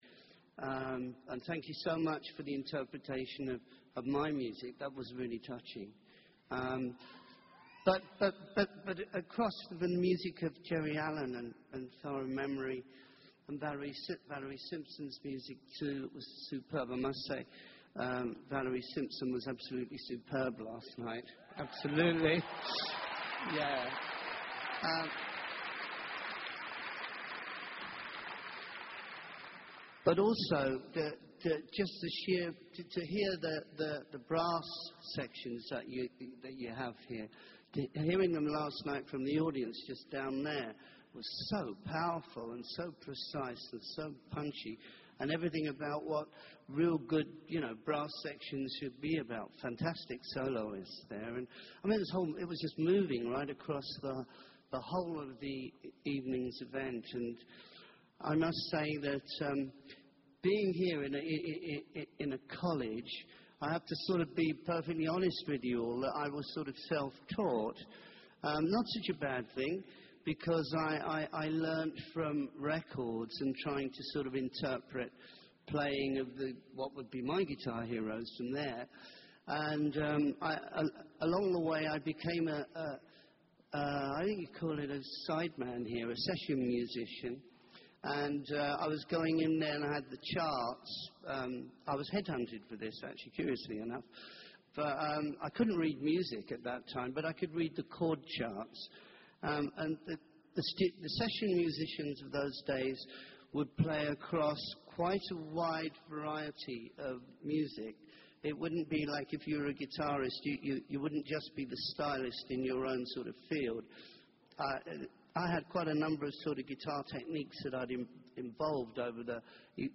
公众人物毕业演讲 第173期:吉米佩吉2014在伯克利音乐学院(2) 听力文件下载—在线英语听力室